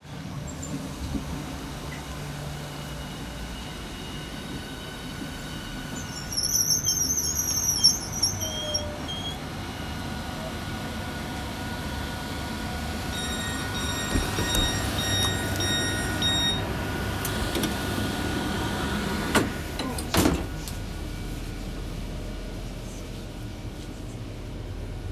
On a train
I got on today’s train to go see Félicia Atkinson and Chris Watson, so it seemed fitting to record its arrival.